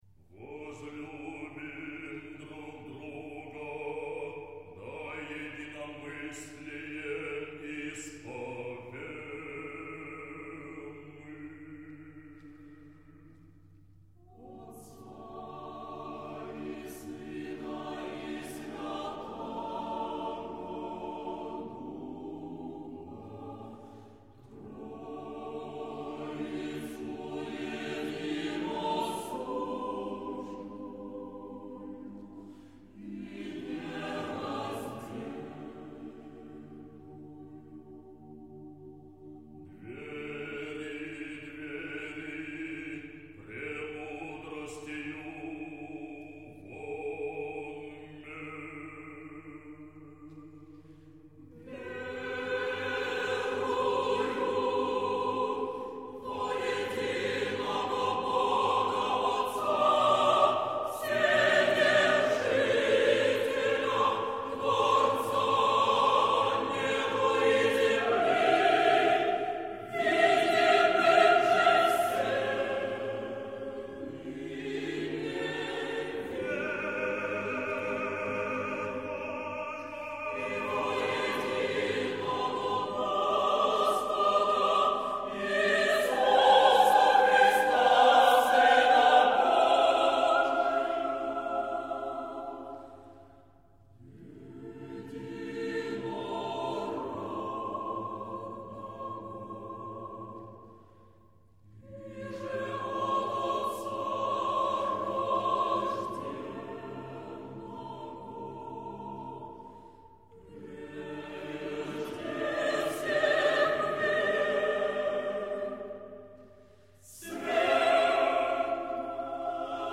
Вокальный ансамбль "Тебе поем" | Мой Красноярск — народная энциклопедия
Литургии